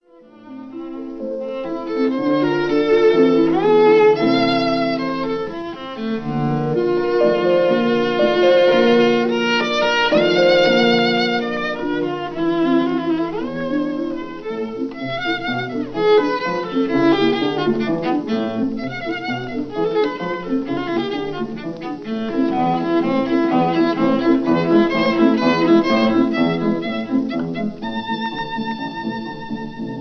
cello
piano